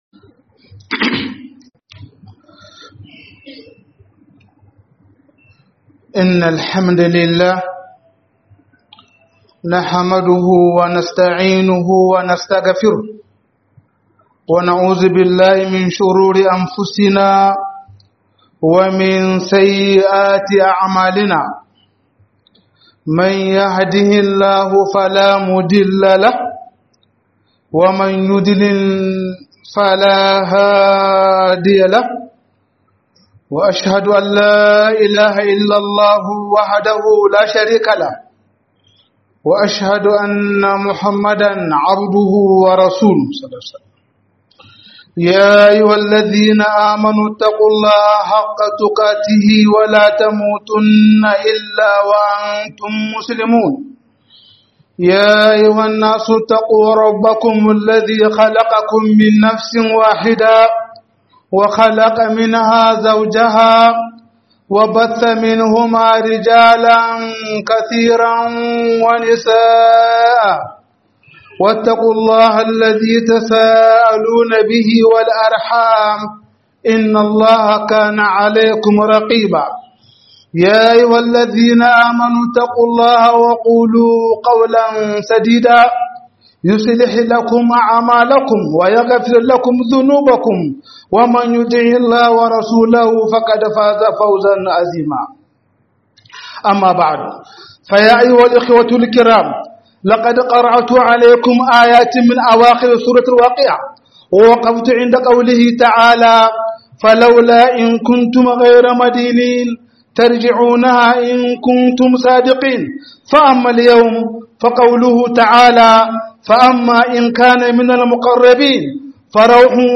007 Rabkanar dan Adam 04 - HUDUBA